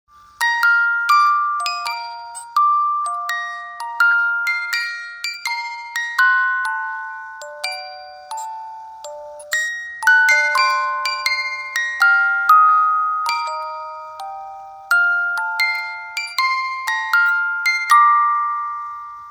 Romántico